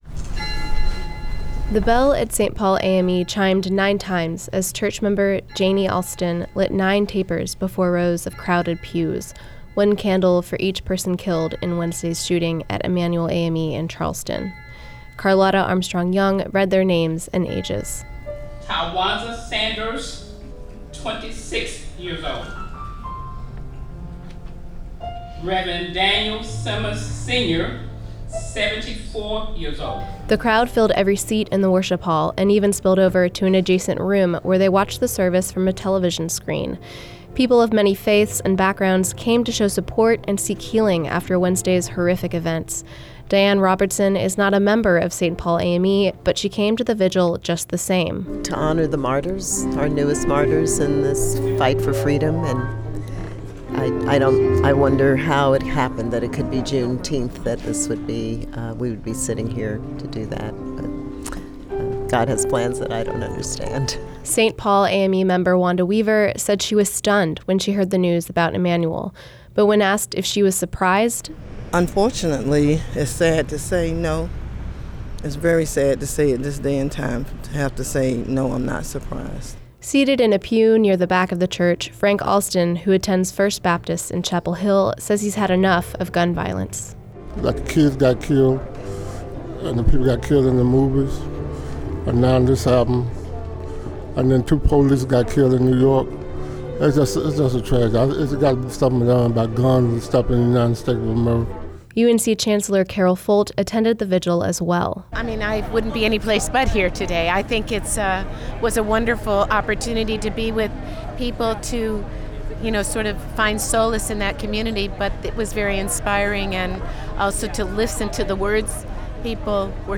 Faith communities gathered at St. Paul AME Church in Carrboro Friday to mourn Wednesday’s shooting deaths of nine members of Emanuel AME Church in Charleston, South Carolina.
The crowd filled every seat in the worship hall and even spilled over to an adjacent room where they watched the service from a television screen.
UNC Chancellor Carol Folt attended the vigil as well.
Faith leaders from six churches and one synagogue took turns leading the crowd in prayer and worship.
St_Paul_AME_vigil_WRAP.wav